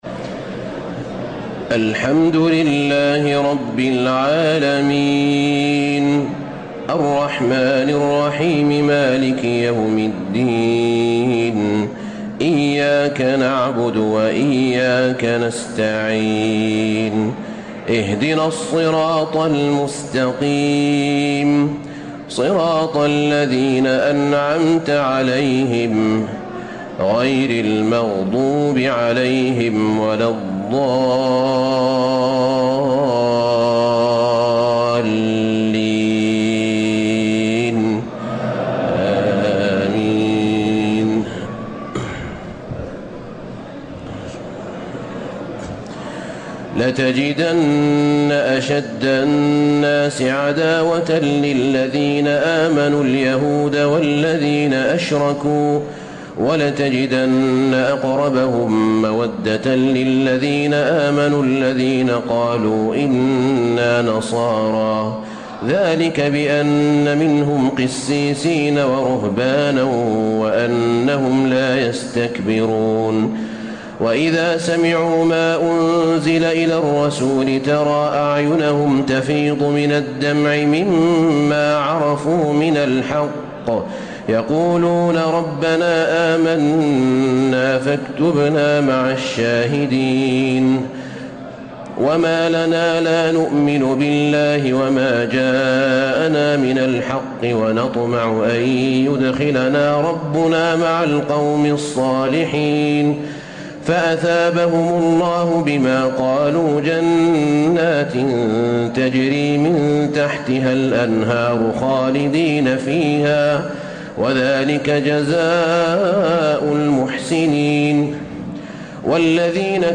تهجد ليلة 27 رمضان 1434هـ من سورتي المائدة (82-120) و الأنعام (1-58) Tahajjud 27 st night Ramadan 1434H from Surah AlMa'idah and Al-An’aam > تراويح الحرم النبوي عام 1434 🕌 > التراويح - تلاوات الحرمين